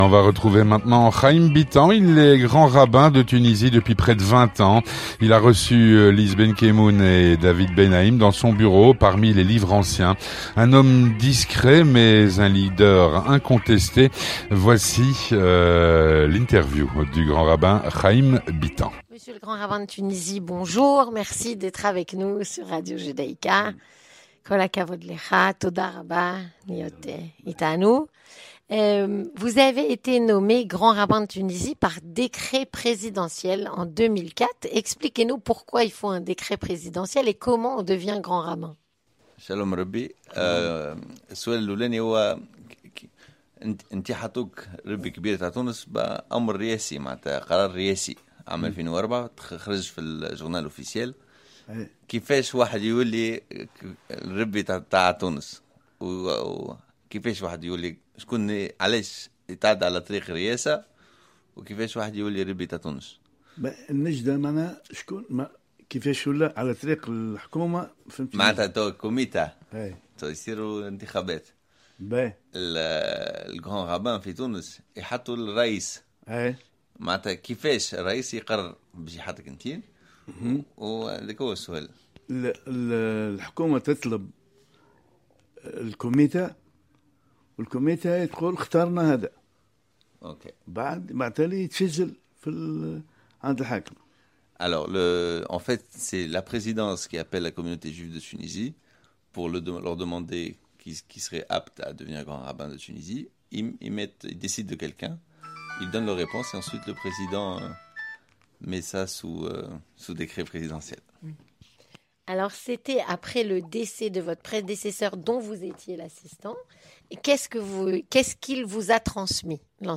Rencontre avec le grand rabbin de Tunisie, Haïm Bitan (10/05/2023)